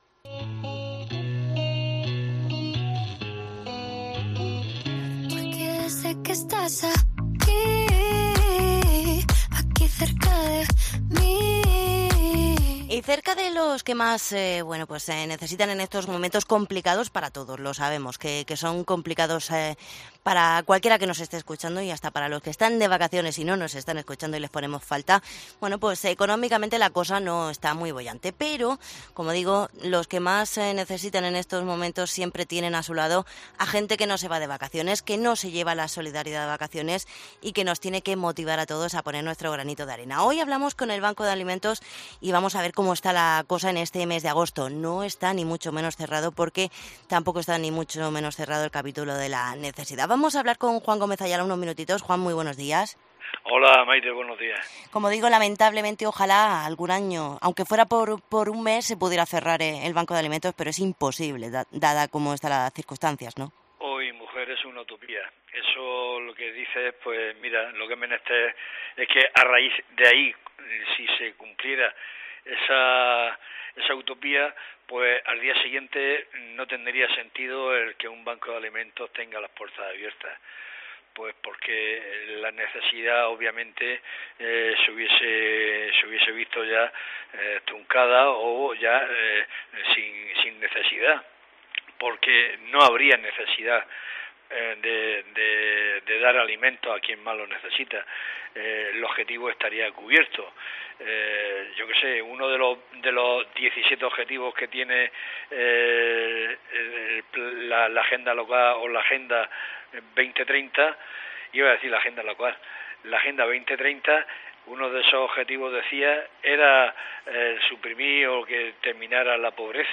Entrevista con el Banco de alimentos